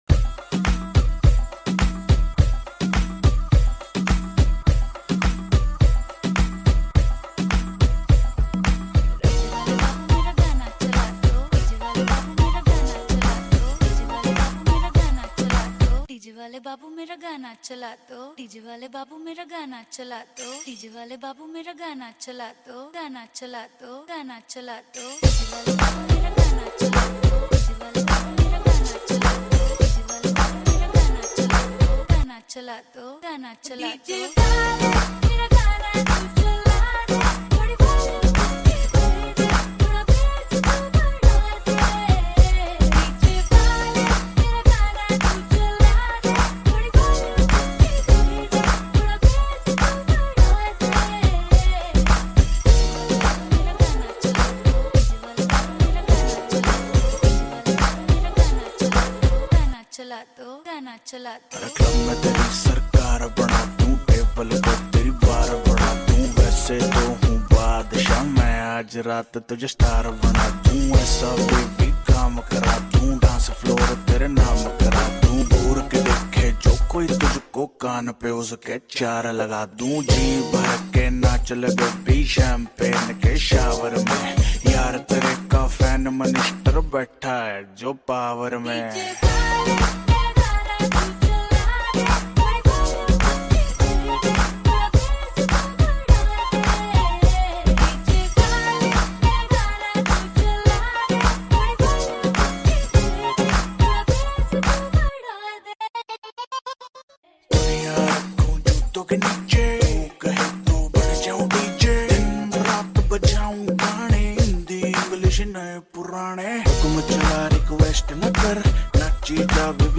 Sinhala Remix New Song